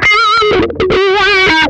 MANIC WAH 14.wav